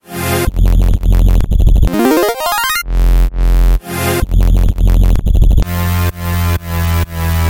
肮脏的电子B 128bpm
Tag: 128 bpm Electro Loops Bass Loops 1.26 MB wav Key : Unknown